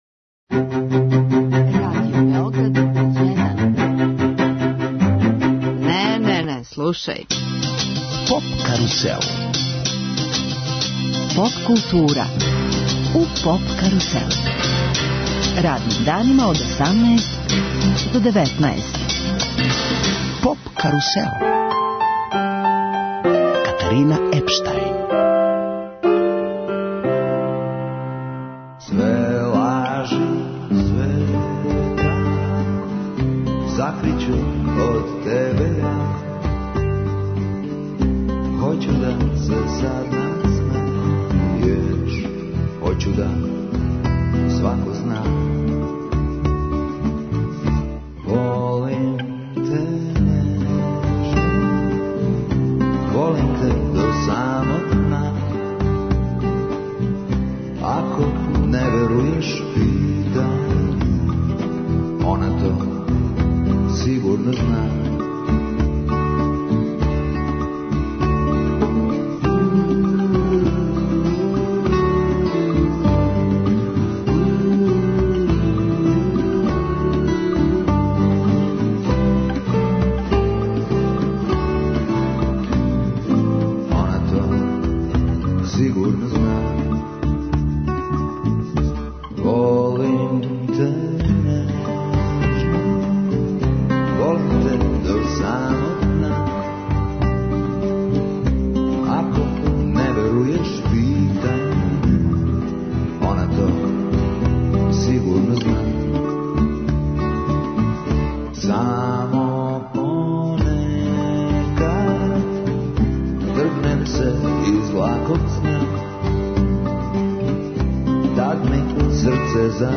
Емисија се реализује из Шапца са Шабачког летњег фестивала.